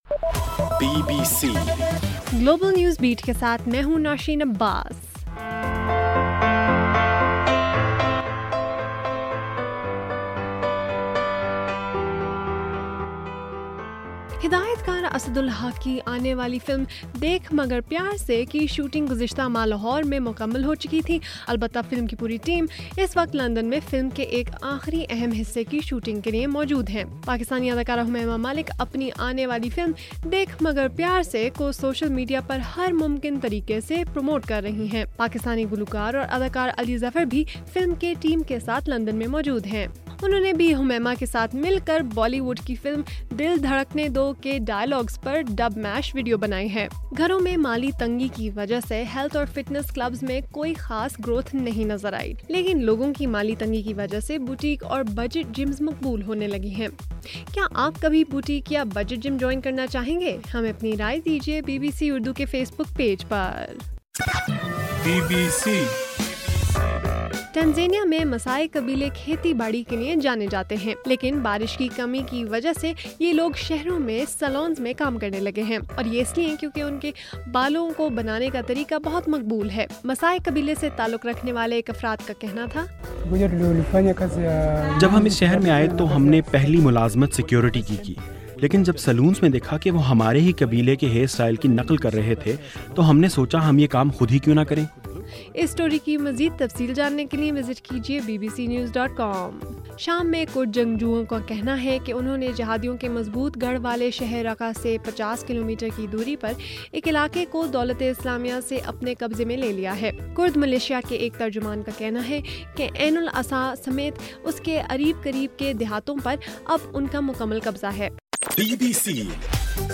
جون 23: رات 12 بجے کا گلوبل نیوز بیٹ بُلیٹن